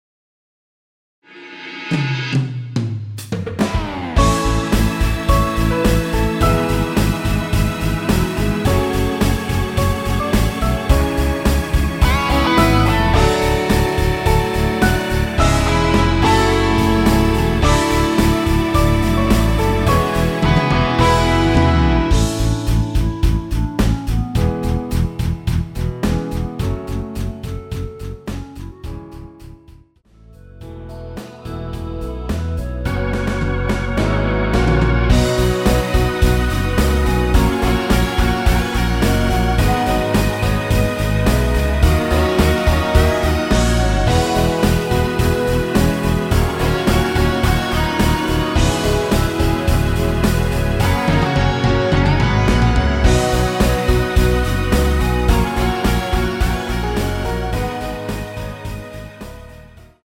원키에서(-3)내린? 멜로디 포함된 MR입니다.
앞부분30초, 뒷부분30초씩 편집해서 올려 드리고 있습니다.
중간에 음이 끈어지고 다시 나오는 이유는